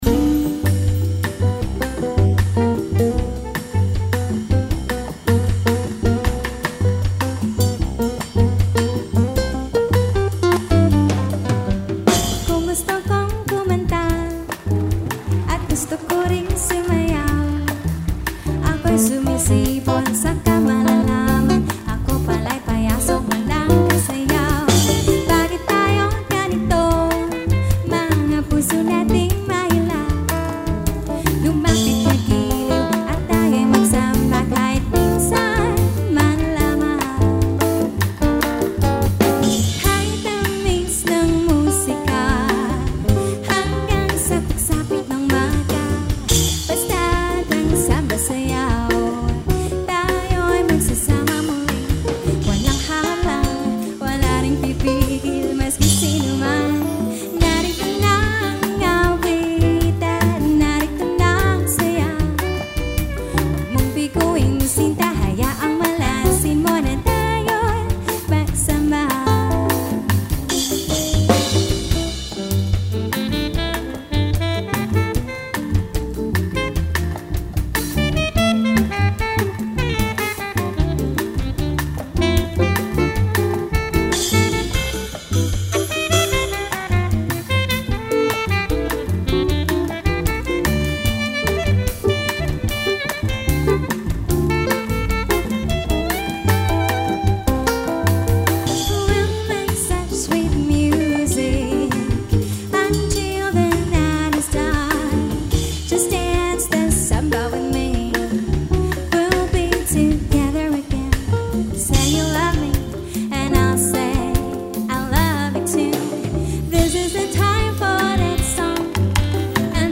柔美的爵士女声总是让人喜爱